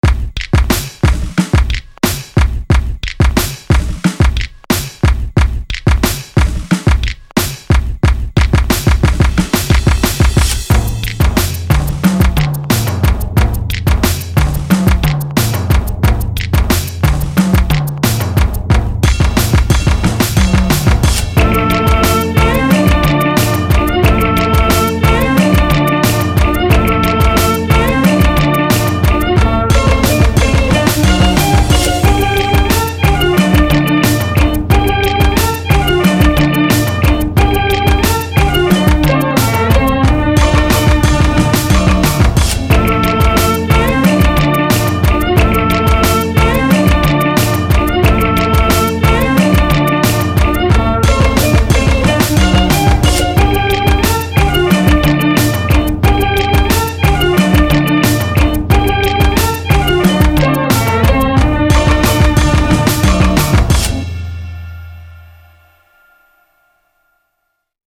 [inst.] 조선시대 저잣거리 비트 | 리드머 - 대한민국 힙합/알앤비 미디어